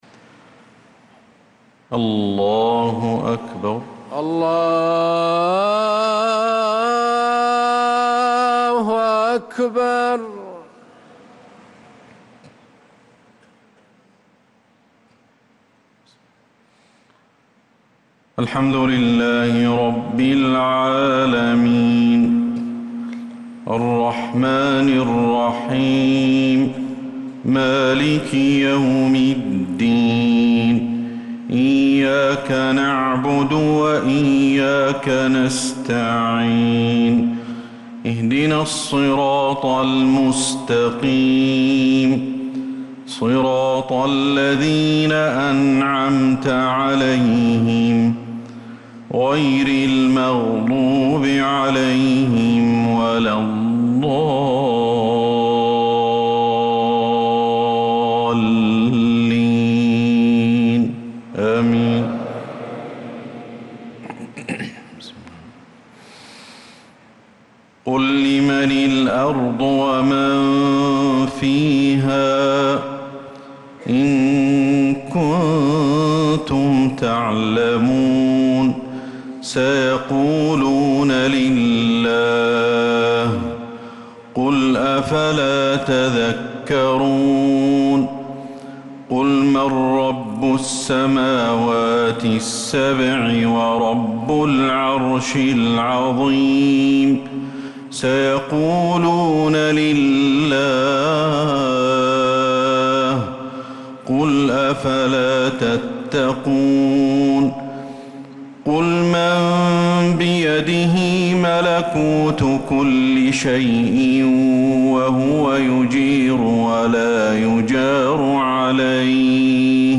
صلاة العشاء للقارئ أحمد الحذيفي 9 صفر 1446 هـ
تِلَاوَات الْحَرَمَيْن .